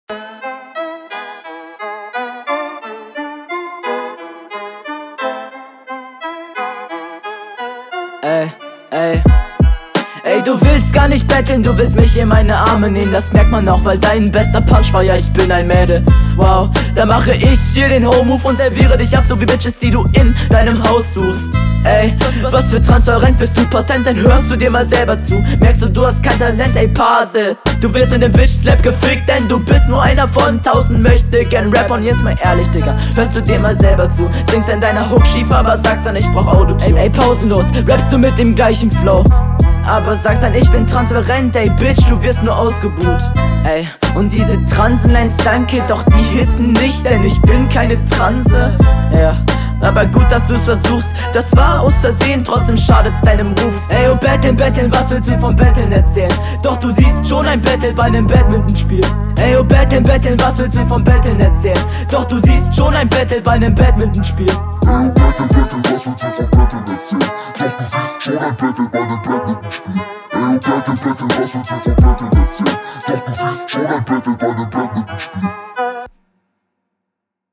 das gechillte "ey ey" kommt cool im intro und dann einstieg direkt schön druckvoll aber …